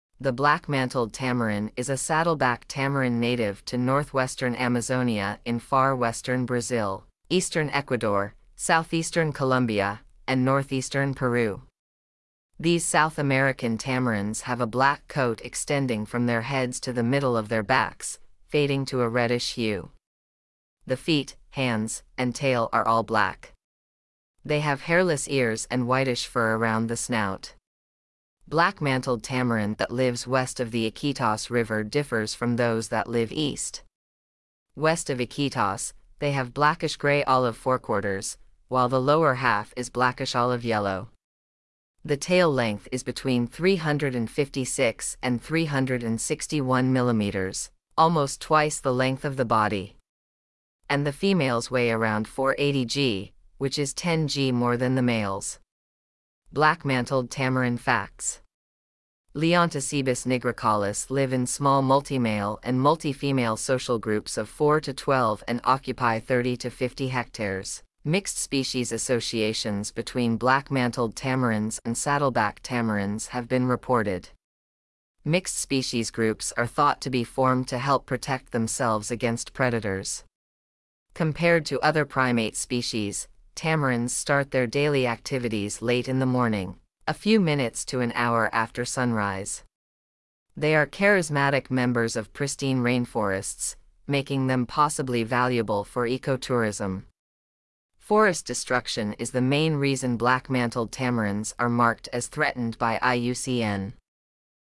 Black-mantled Tamarin
Black-mantled-Tamarin.mp3